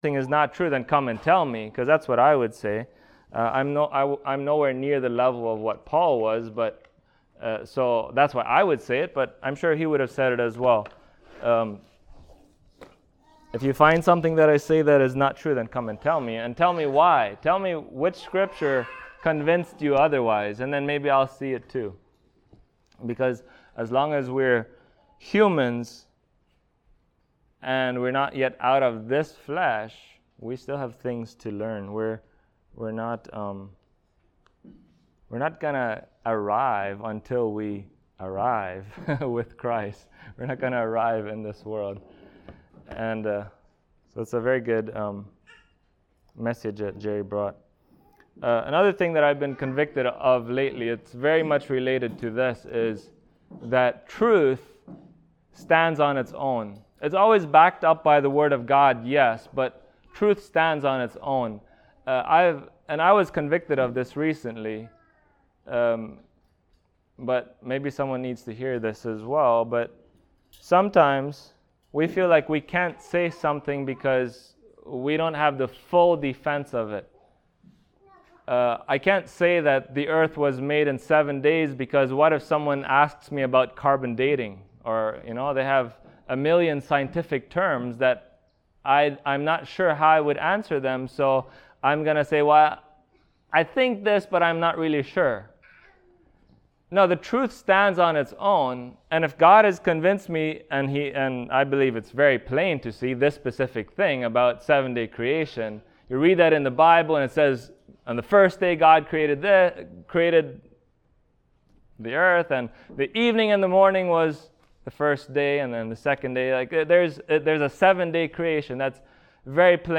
Proverbs Passage: Prov 14:26-27 Service Type: Sunday Morning